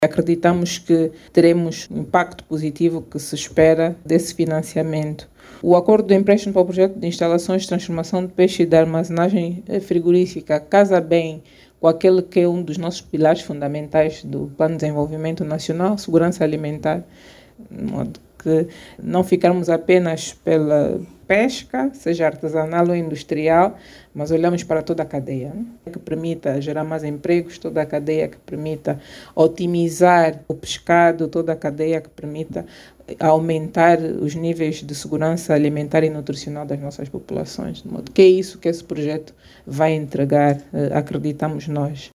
A ministra das Finanças, Vera Daves de Sousa, esclareceu que a maior parte do financiamento será alocada a projectos ligados ao desenvolvimento da indústria pesqueira nacional.